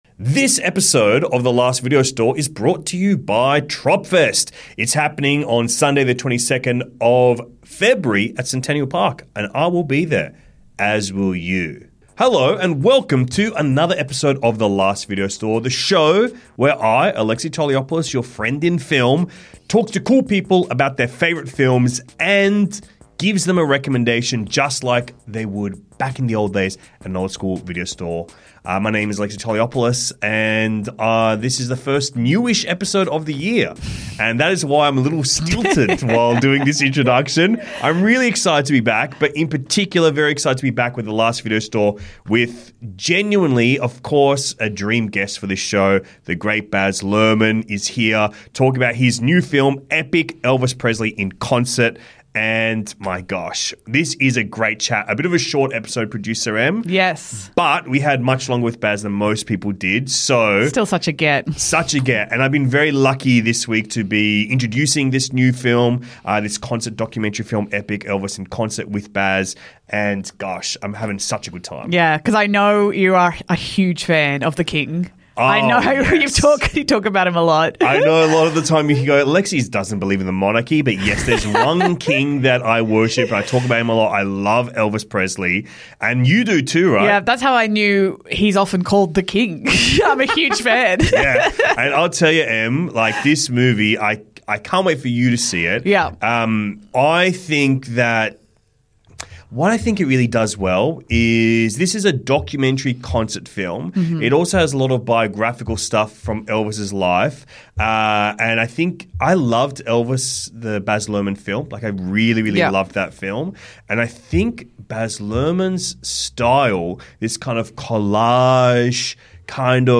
What an immense privilege to chat with true visionary filmmaker Baz Luhrmann about his new film EPiC: ELVIS PRESLEY IN CONCERT.